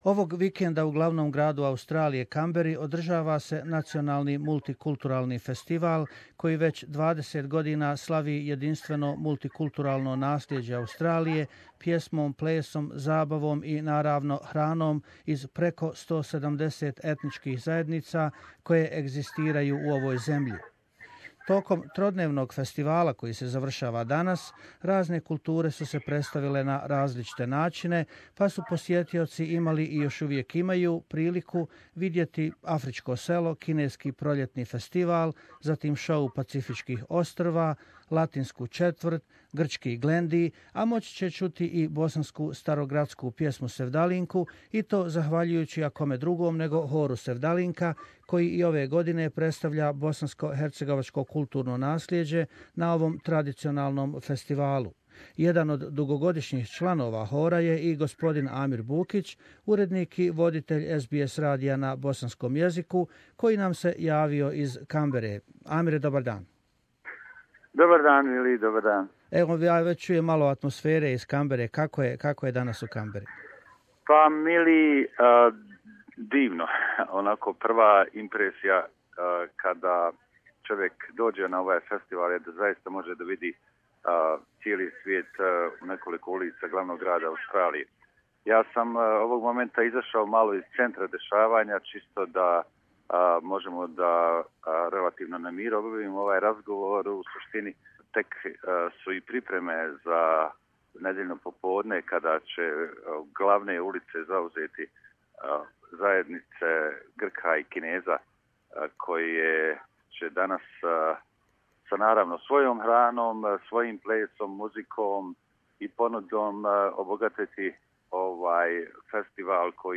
This weekend, the capital of Australia, Canberra hosted the National Multicultural Festival, which traditionally for 20 years celebrates multiculturalism in Australia. Choirs Blue River from Sydney and Sevdalinka from Melbourne presented part of the Bosnian cultural heritage at the festival.